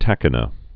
(tăkə-nə)